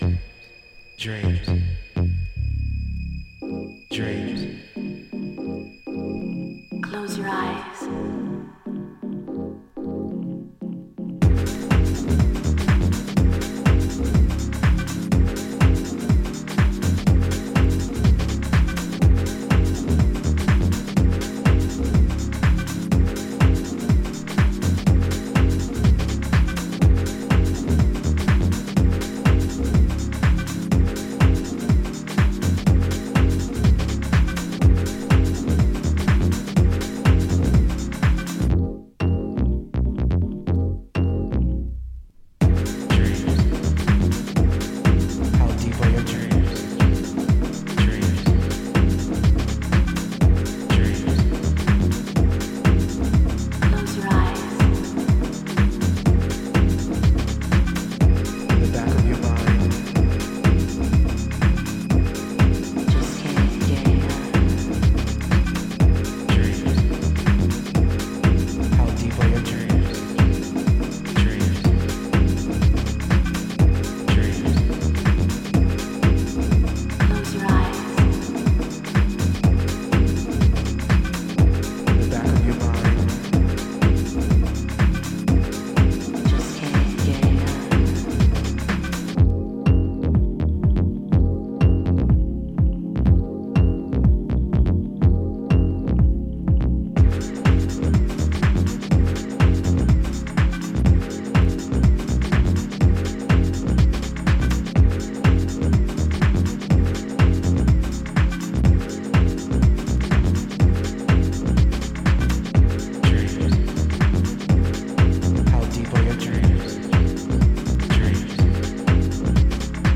今回もシルキーでメロディアスなシンセワークやアナログマシンのダスティなグルーヴを駆使したディープ・ハウスを展開。